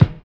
LO FI 2 BD.wav